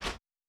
Generic Swing Distant.wav